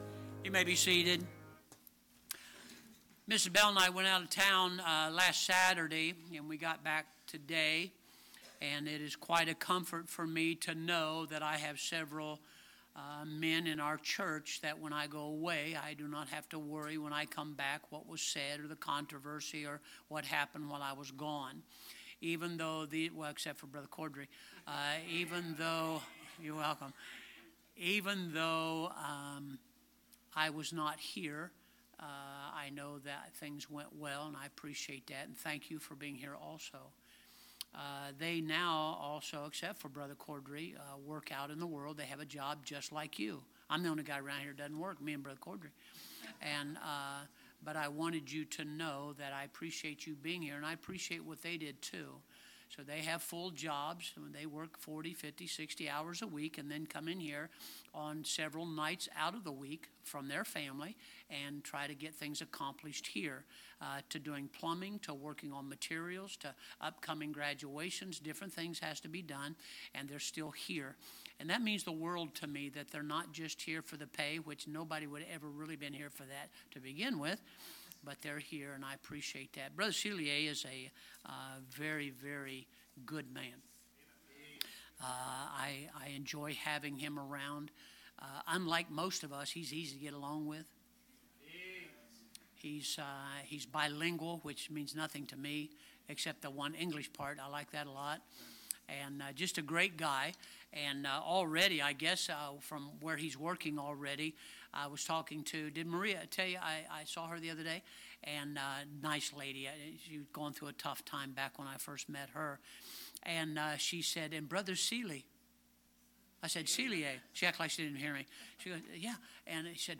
Sermons preached from the pulpit of Anchor Baptist Church in Columbus, Ohio.